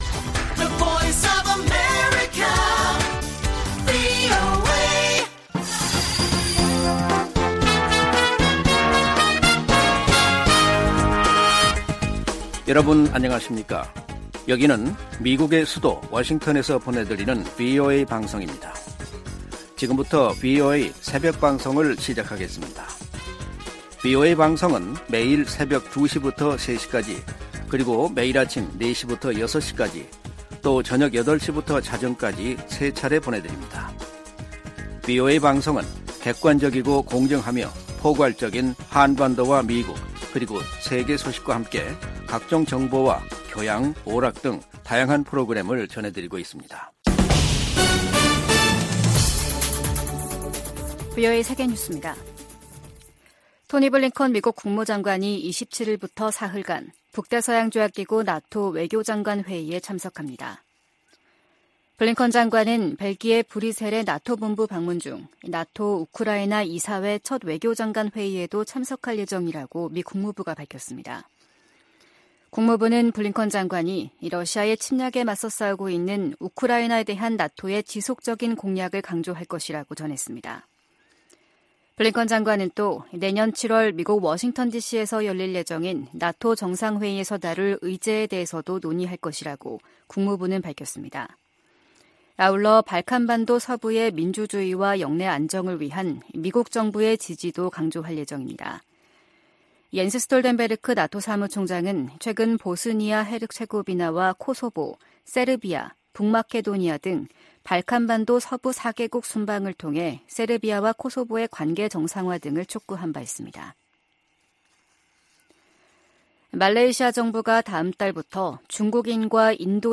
VOA 한국어 '출발 뉴스 쇼', 2023년 11월 28일 방송입니다.